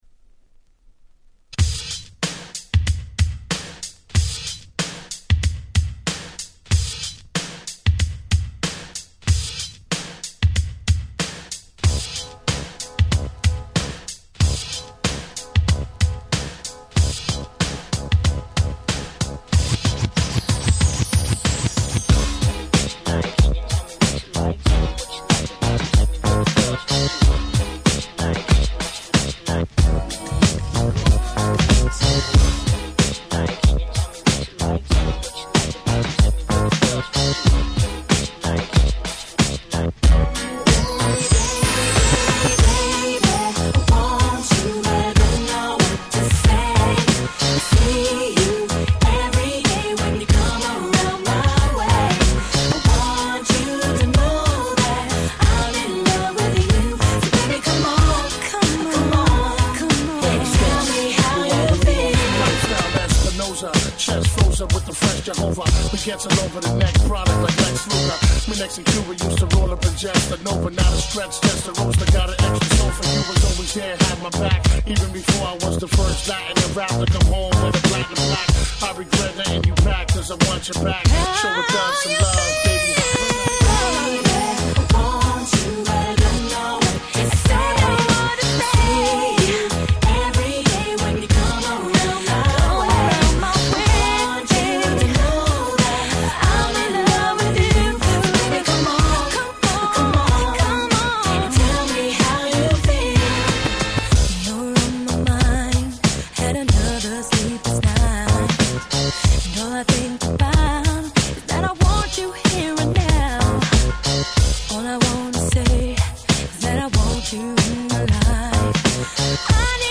キャッチー系